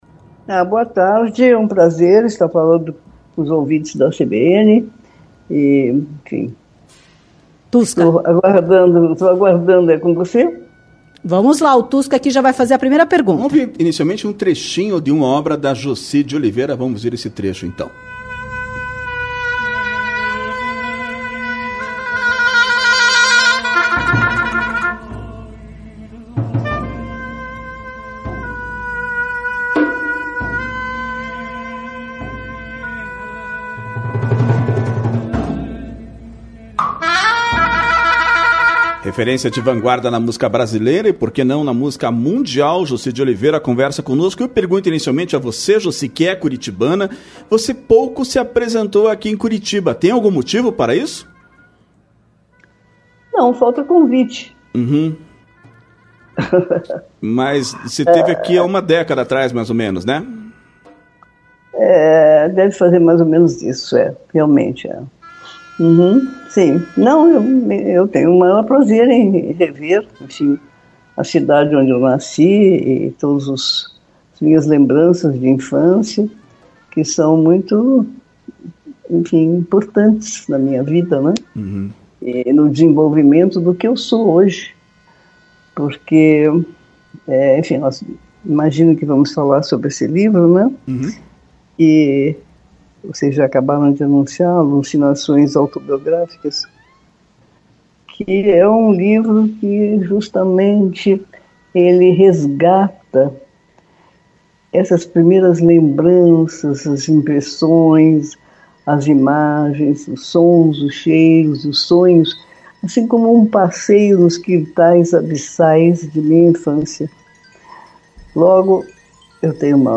Entrevistada pela CBN, a compositora lembrou das dificuldades para montar um trabalho inovador na década de 1960 e falou sobre a ópera apresentada este ano em São Paulo.
Entrevista-Quarta-Cultural-13-11.mp3